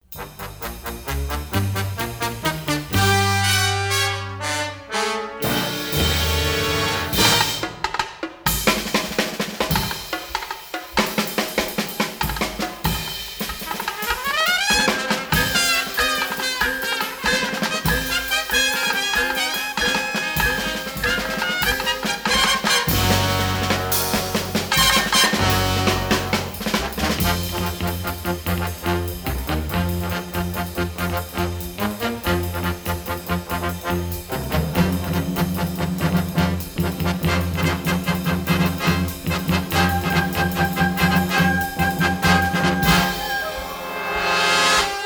are presented in dynamic stereo